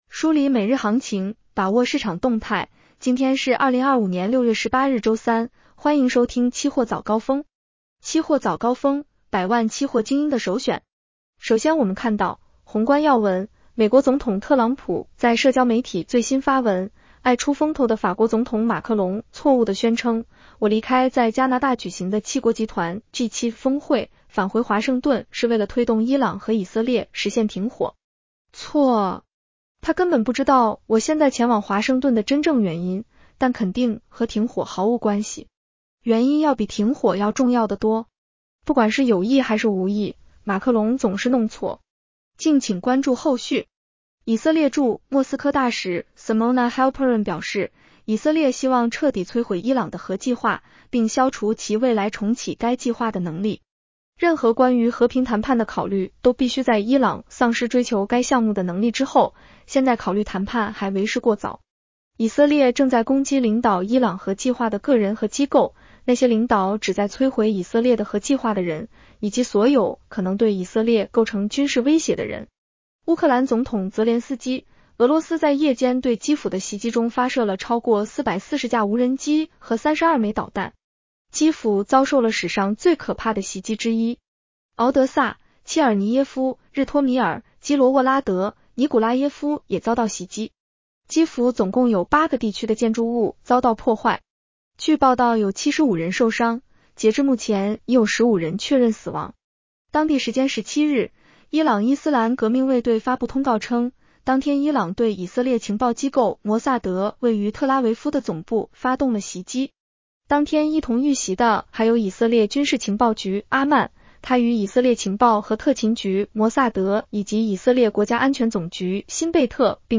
期货早高峰-音频版
女声普通话版 下载mp3